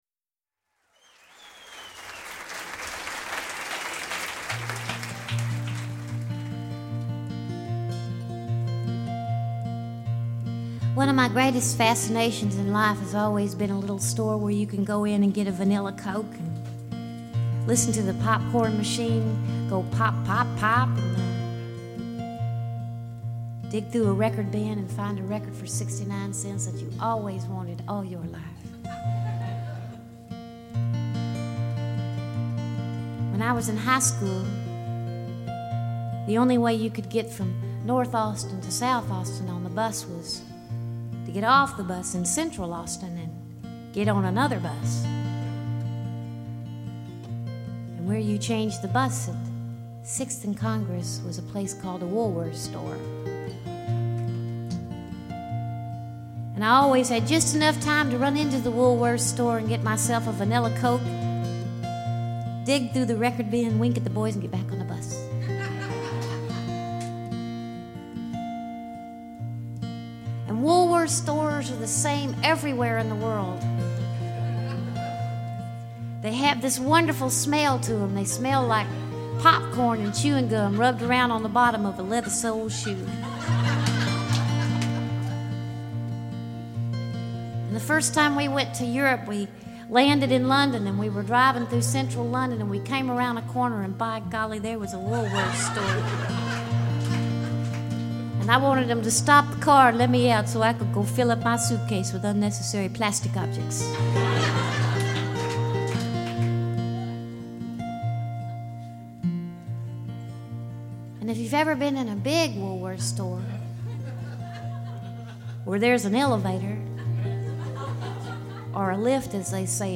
Sweet-voiced songstress